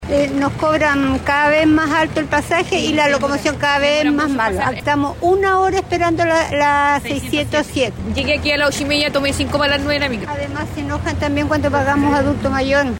En esa línea, Radio Bío Bío consultó a los principales afectados, los usuarios. A juicio de los “pasajeros”, el alza es injustificada si no se traduce en mejor calidad del servicio.